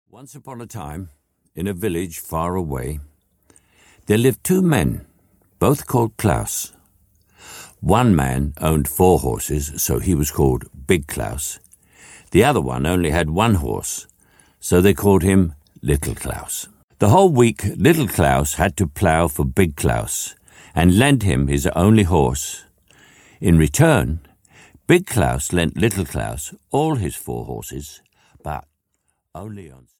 Little Claus and Big Claus (EN) audiokniha
Sir Michael Caine reads "Little Claus and Big Claus".
Ukázka z knihy
• InterpretSir Michael Caine